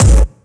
GS Phat Kicks 004.wav